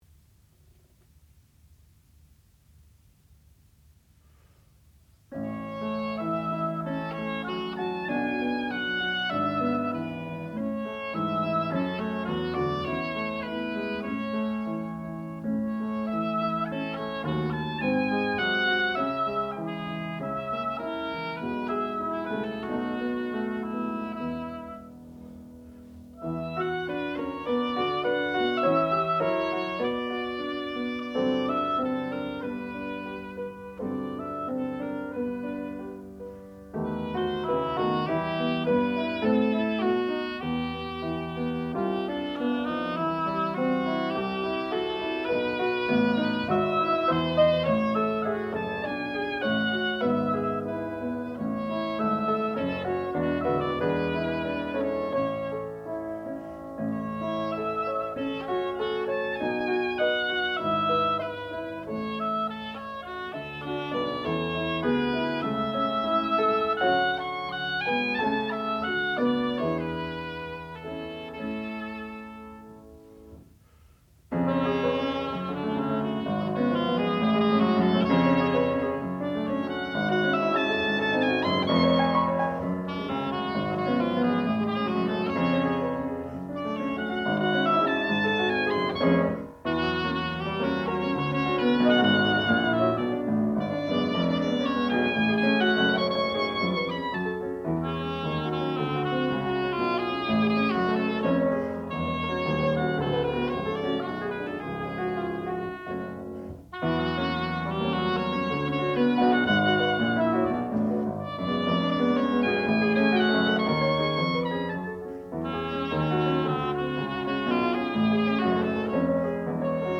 sound recording-musical
classical music
Advanced Recital
oboe